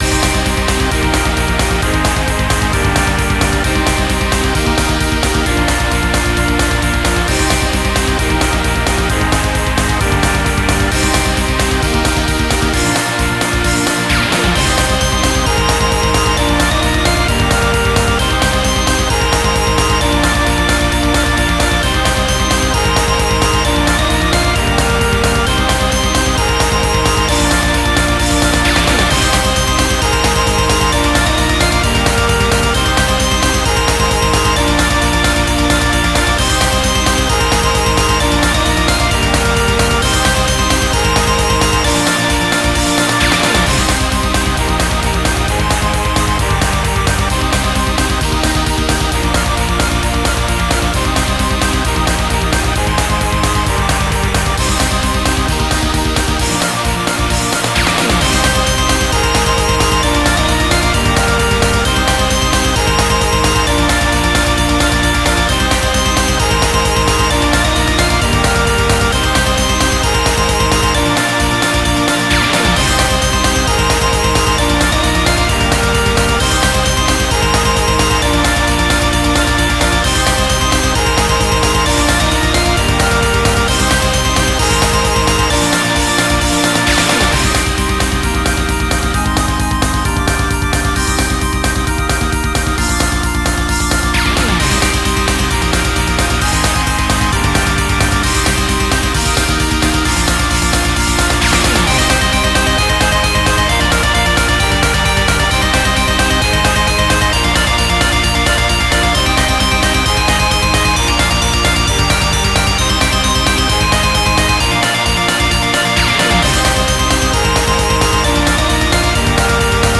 Power Metal Cover)file